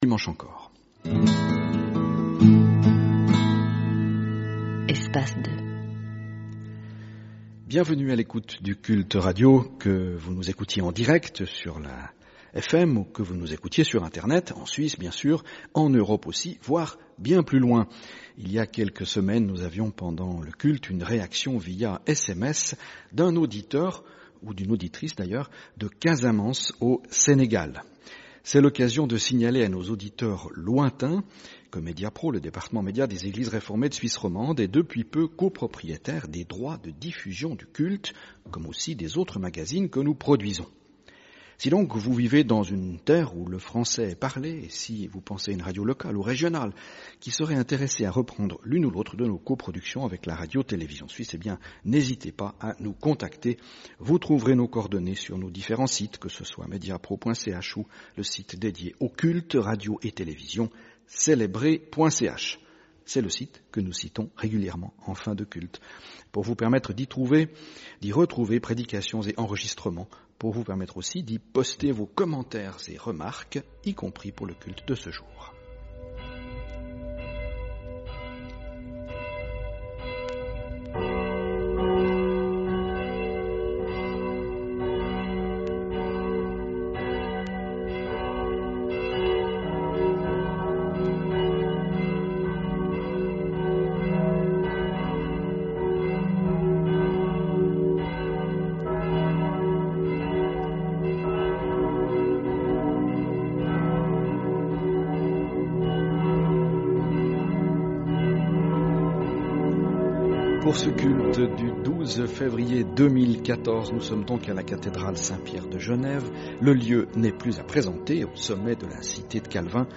Troisième culte d'une série de cultes radiodiffusés à la cathédrale de Lausanne.